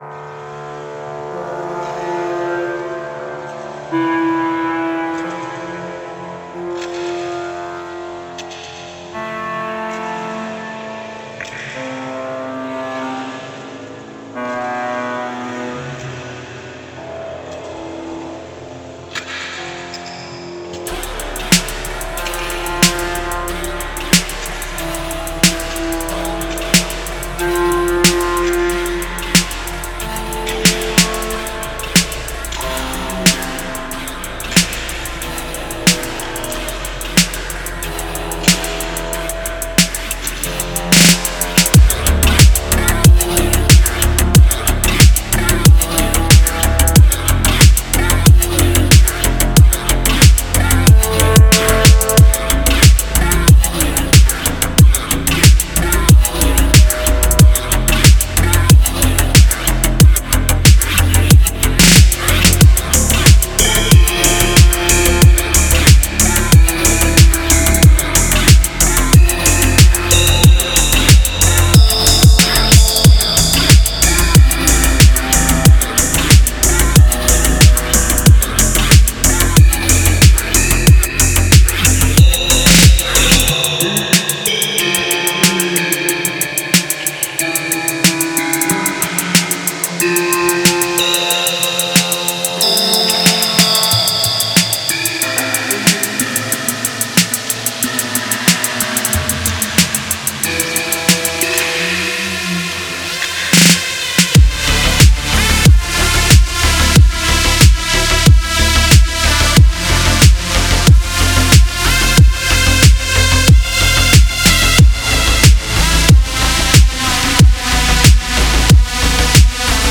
Genre: Witch House.